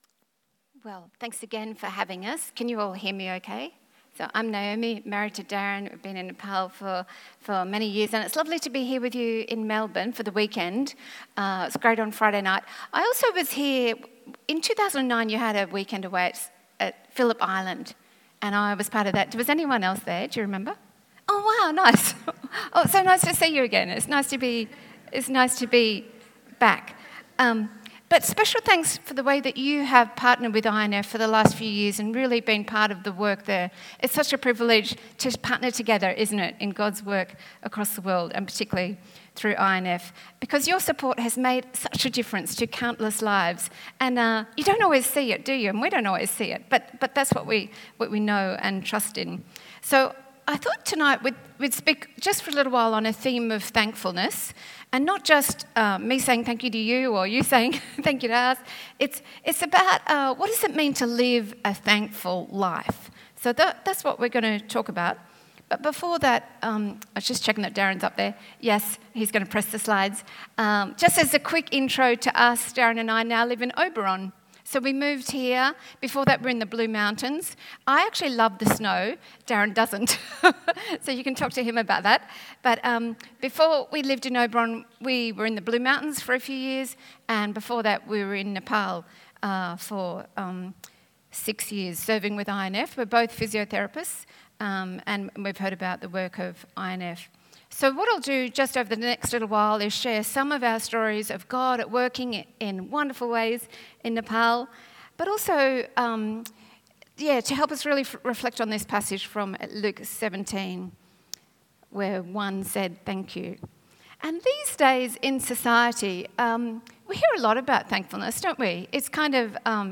Sermon
Mission Sunday Guest Speaker